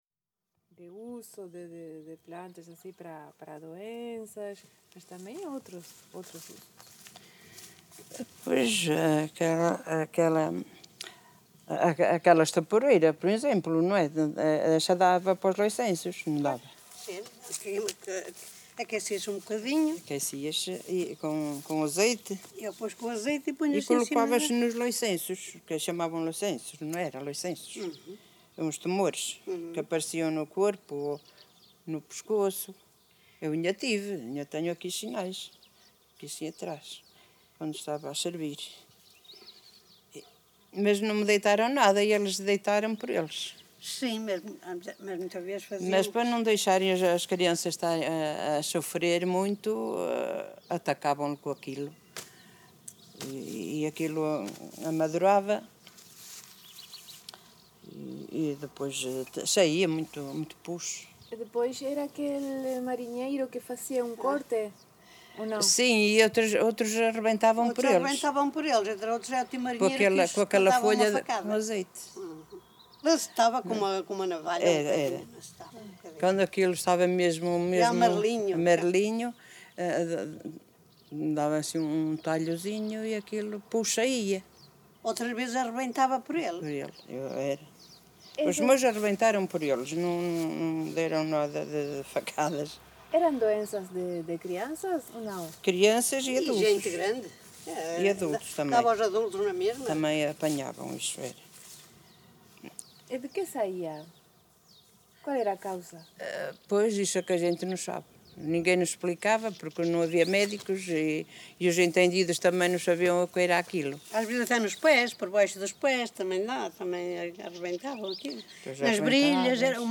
Várzea de Calde, primavera de 2019.